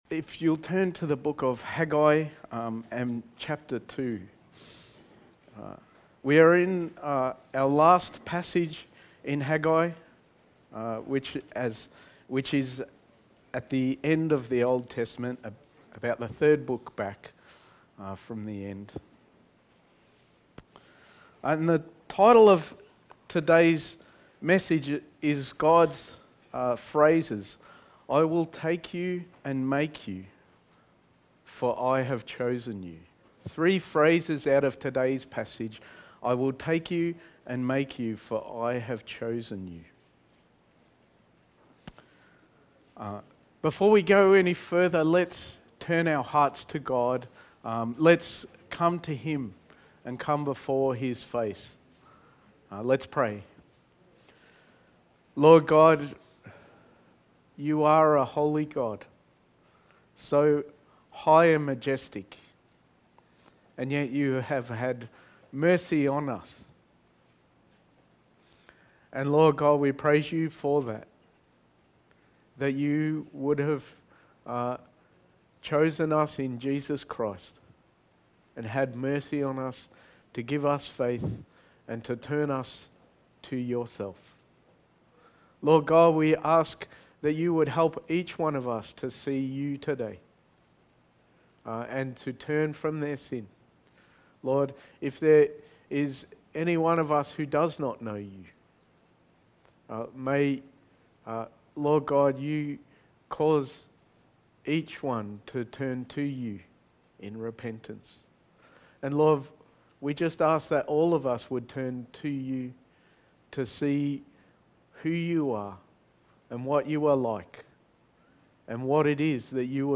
Passage: Haggai 2:20-23 Service Type: Sunday Morning